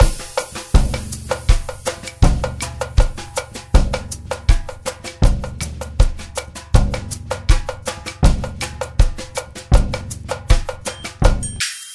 Modern drum loop Free sound effects and audio clips
80 Bpm 2000s Drum Loop Sample A# Key.wav
Free drum beat - kick tuned to the A# note. Loudest frequency: 1329Hz